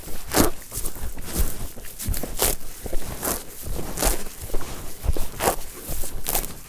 The SIGNAL-based system automatically detects, classifies, and quantifies streaming acoustic recordings of biting and chewing sounds.